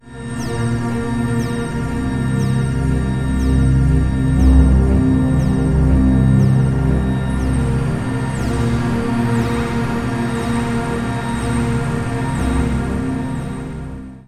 For Pattern 1, I decided that the lower pitch would be a sustained “drone” sound, with the other pitches occurring in a regular, step-wise set of short events.
The instrument “Alionoctis” is a VST synthesizer by HG Fortune, which has some highly appropriate pads and textures.
This sound is designed to “evolve” – the longer you hold down the key, the more interesting the sound gets – so it is just right for this pattern with its sustained lower pitch.
Apart from applying a Volume envelope, no other effects are necessary with this very rich pad.